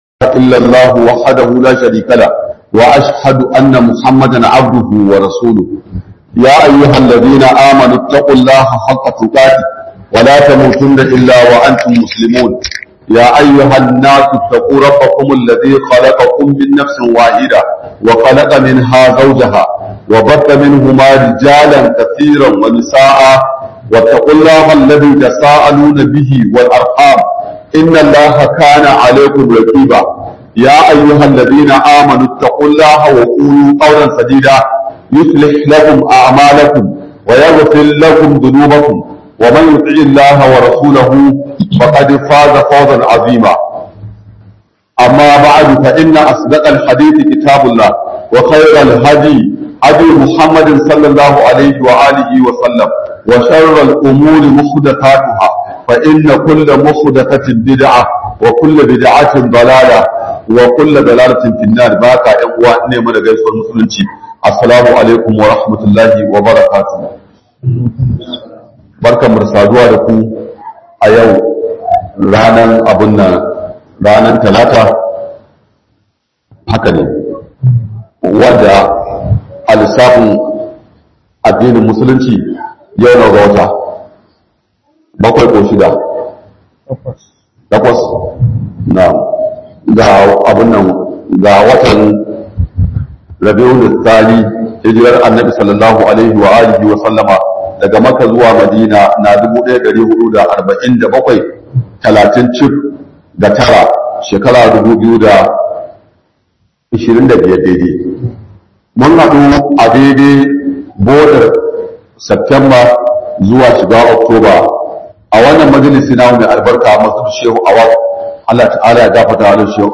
Fa'idar Wayar Hanu Da Sharrinta - Muhadara